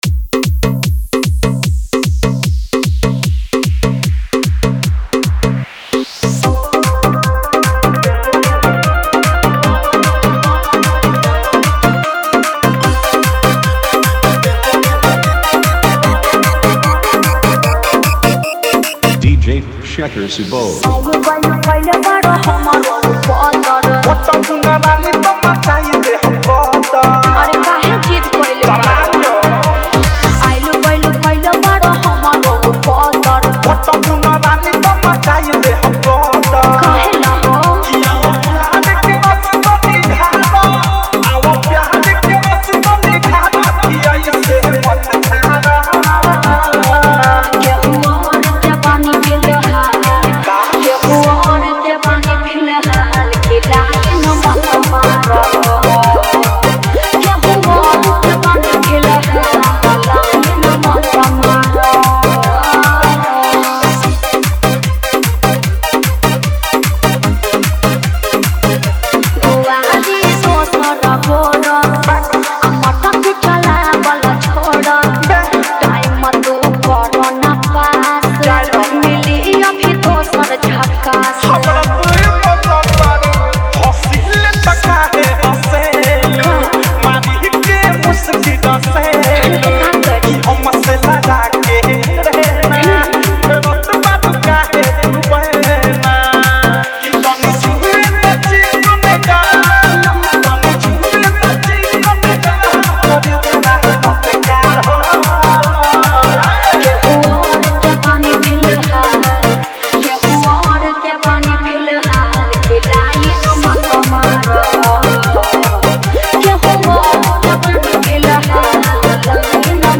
Bhojpuri DJ Remix Song
Bhojpuri DJ Remix Songs